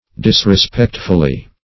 Dis`re*spect"ful*ly, adv.